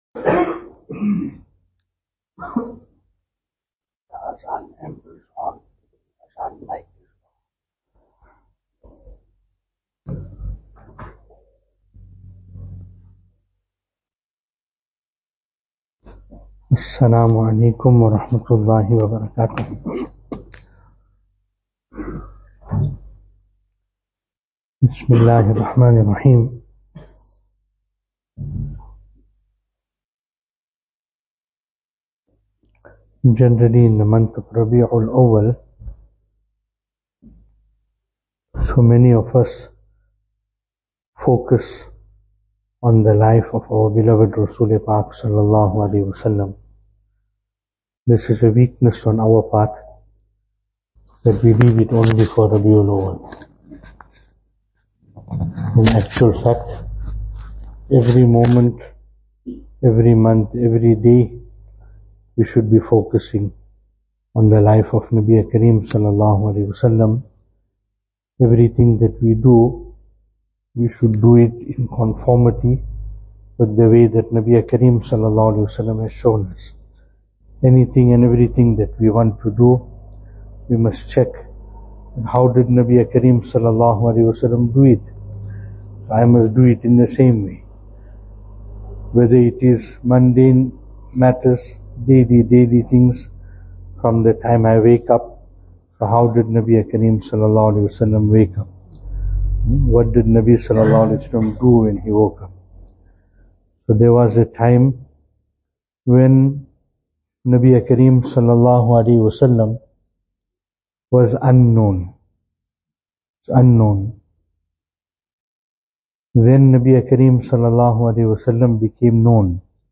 Venue: Albert Falls , Madressa Isha'atul Haq Service Type: Jumu'ah